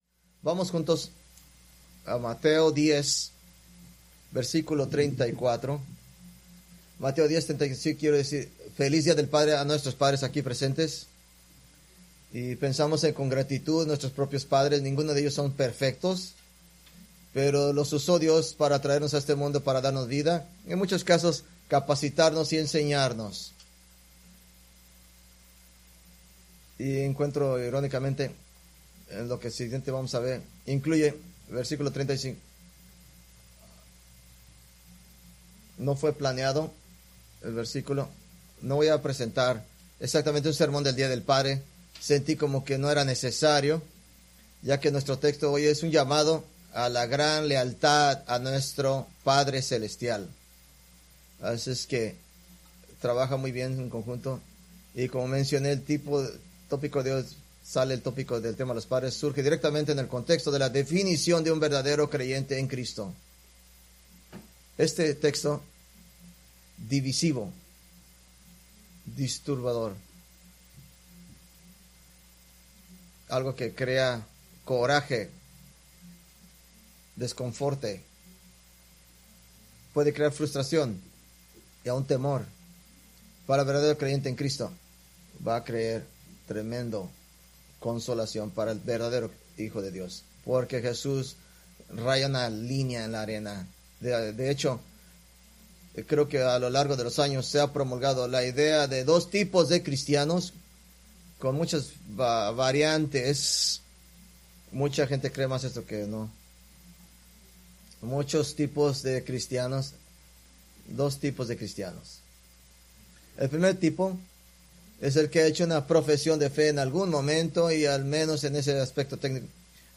Preached June 15, 2025 from Mateo 10:34-39